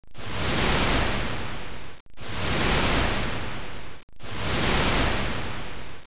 Ship
Ship.wav